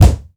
punch_low_deep_impact_10.wav